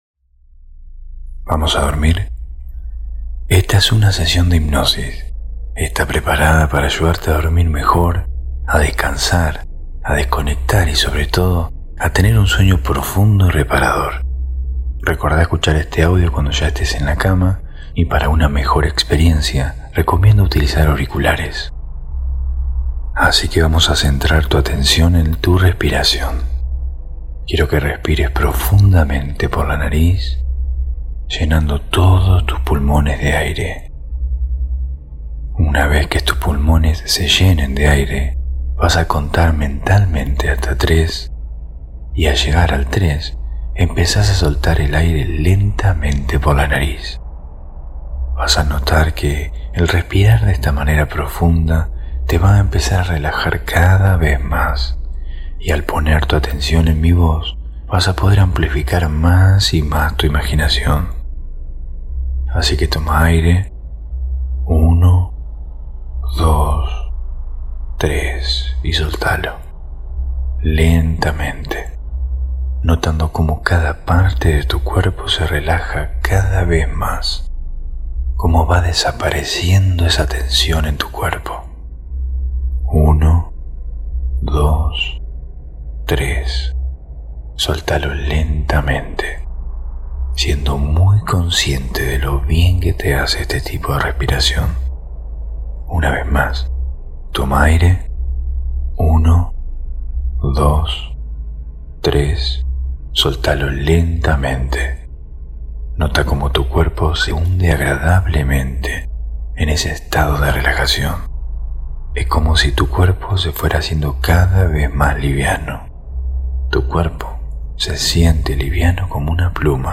Hipnosis para Dormir 5